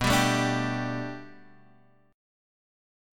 Bm7#5 chord